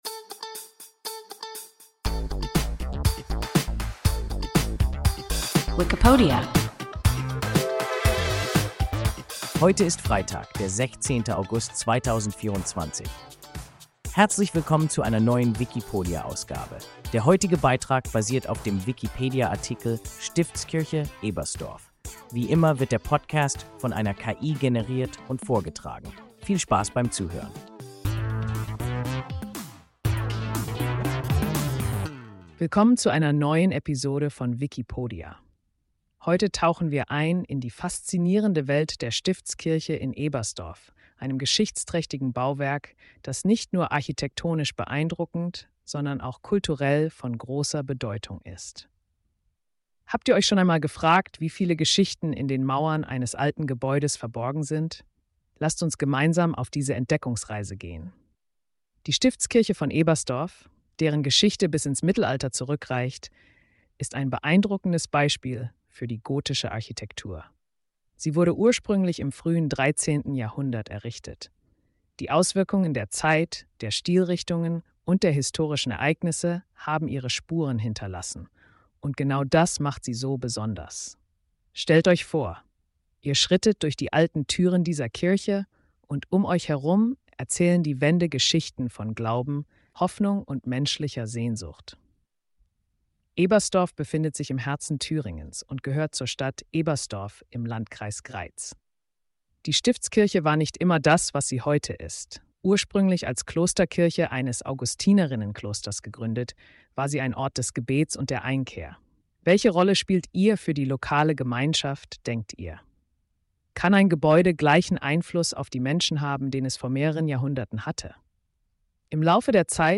Stiftskirche (Ebersdorf) – WIKIPODIA – ein KI Podcast